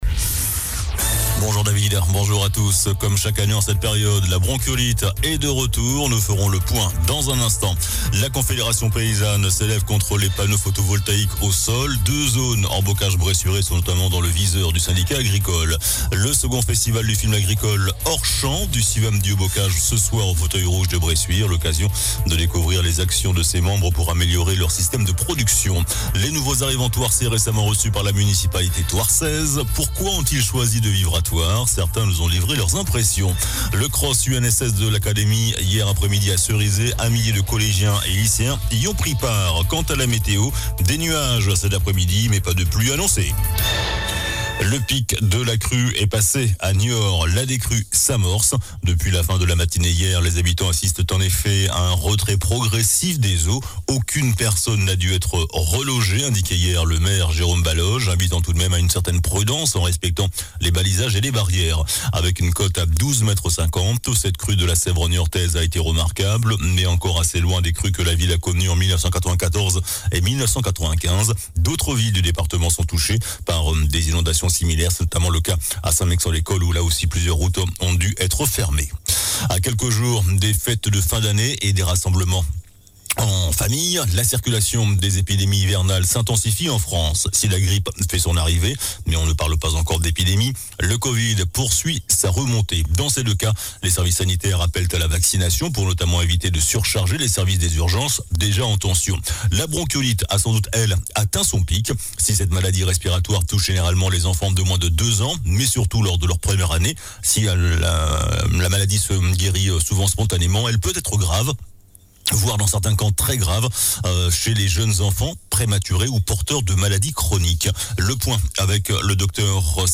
JOURNAL DU JEUDI 14 DECEMBRE ( MIDI )